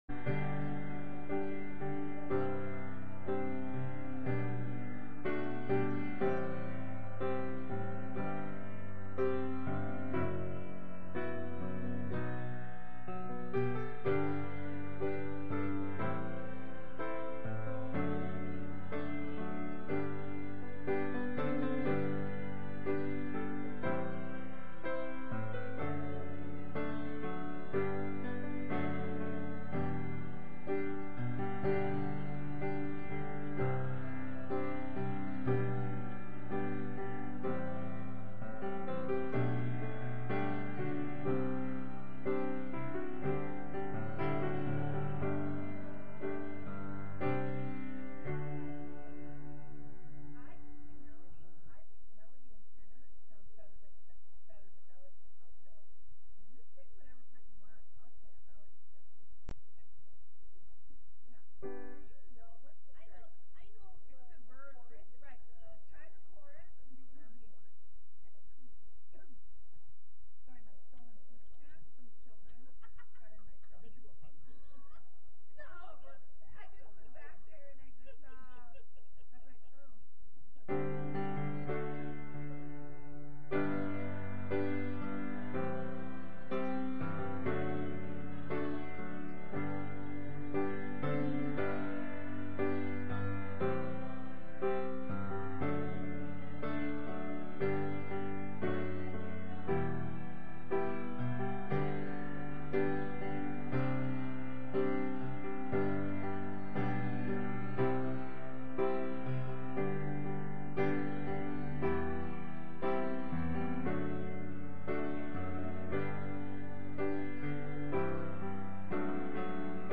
Sermons | Grace Lutheran Church